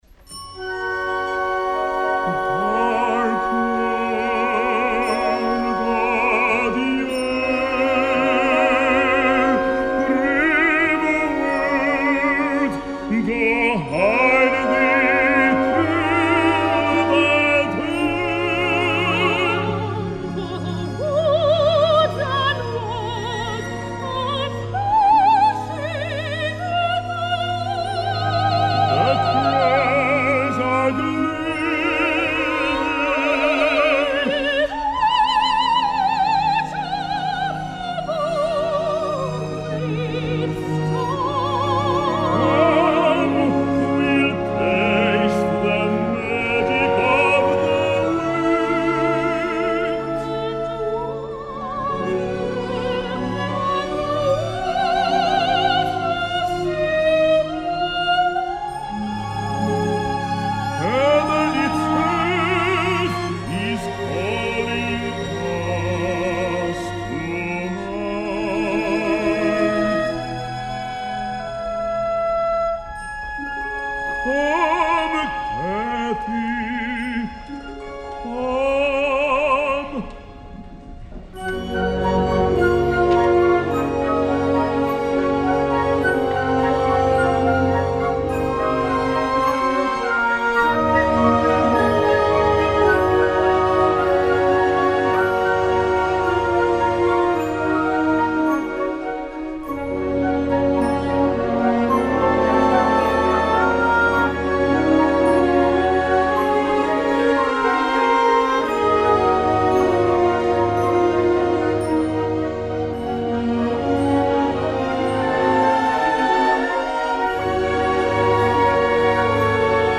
Òpera en un pròleg i 4 actes
Versió de concert.